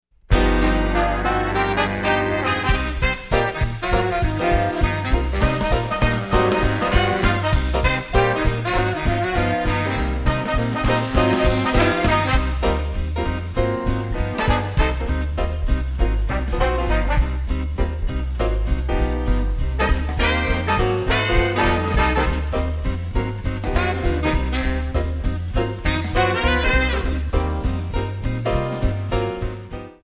Cm
MPEG 1 Layer 3 (Stereo)
Backing track Karaoke
Pop, Jazz/Big Band, 1990s